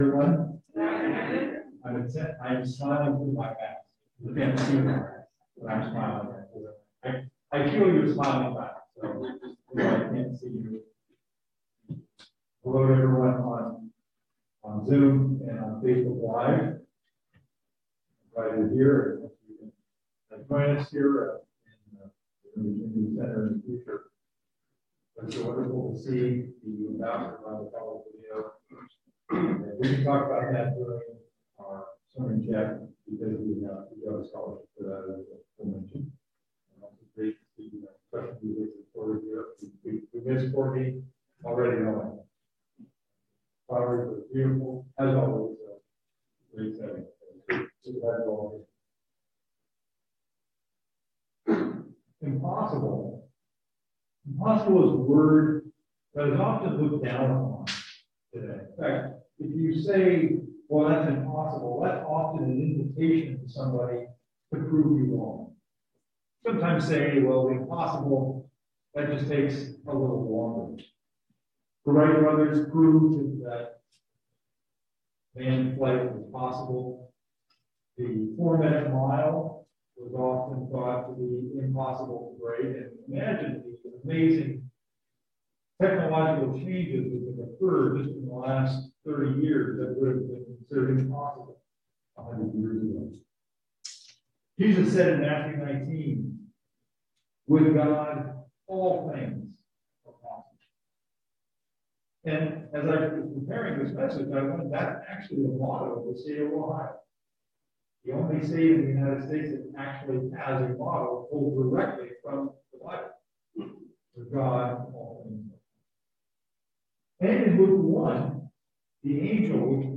5/15/21 This sermon explores the warning given to believers in Hebrews 6:4 about rejecting our calling.